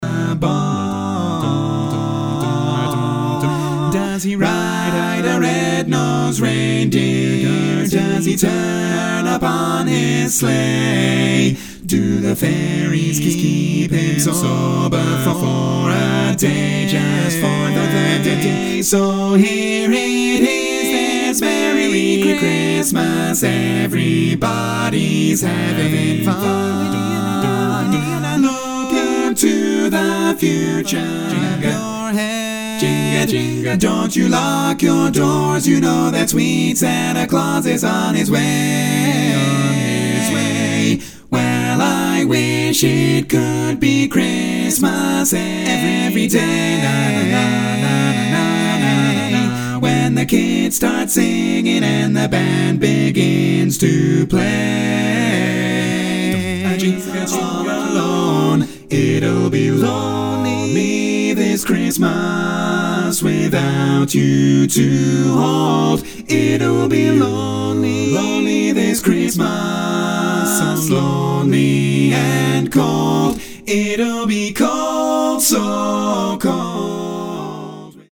Category: Male